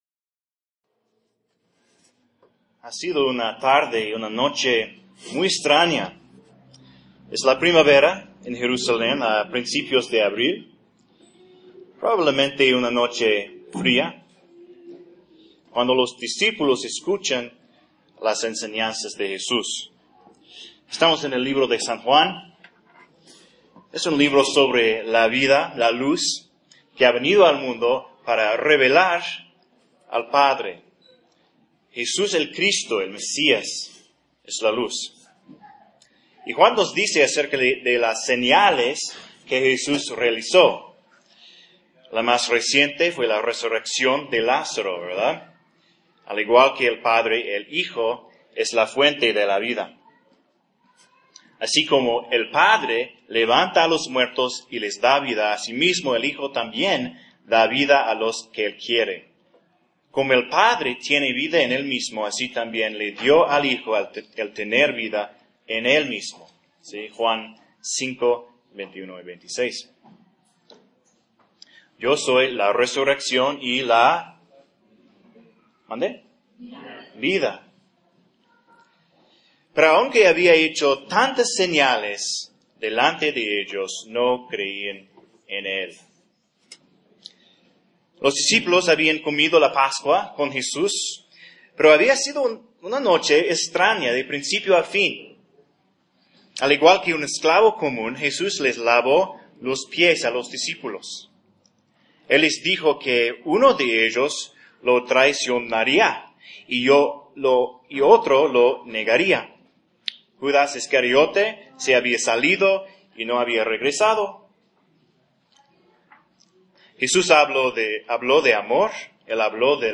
Un sermón de San Juan 15:1-16:4.